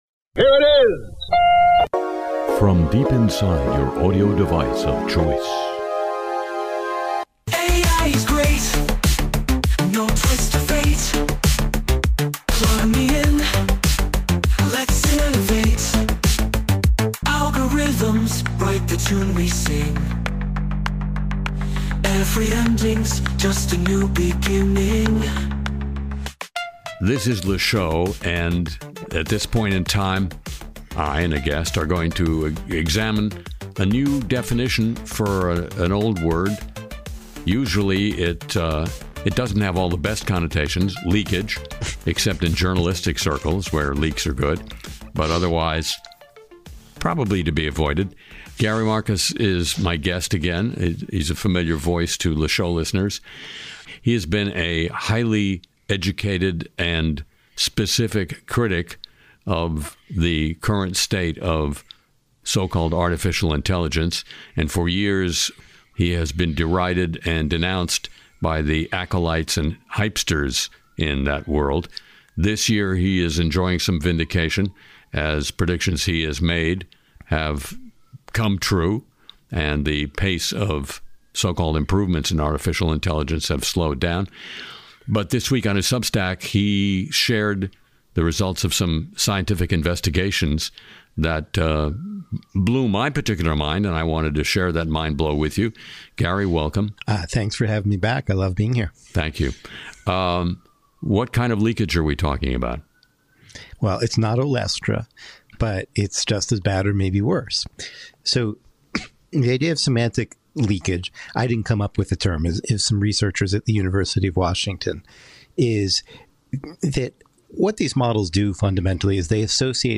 Part-time New Orleans resident Harry Shearer hosts a look at the worlds of media, politics, cyberspace, sports and show business while providing an eclectic array of music along the way.